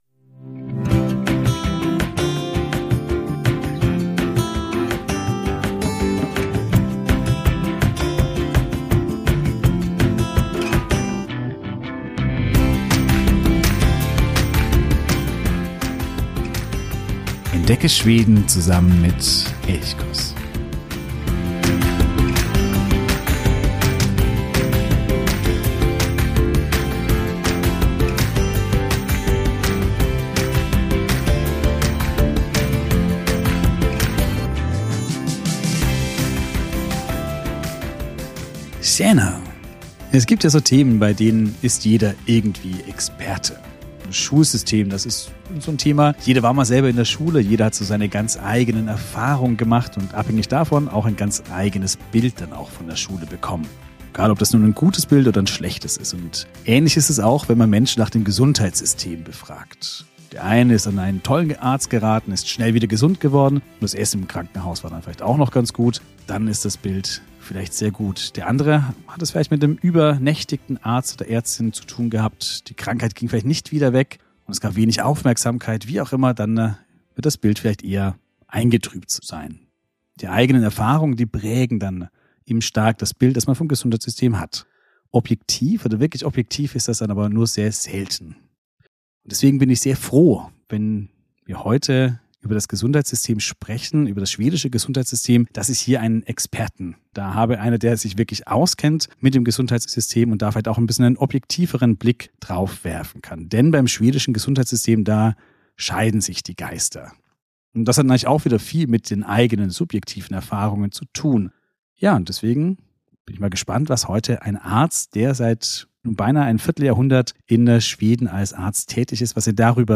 Mit ihm unterhalte ich mich über das Gesundheitssystem Schwedens.